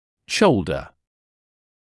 [‘ʃəuldə][‘шоулдэ]плечо; плечевой сустав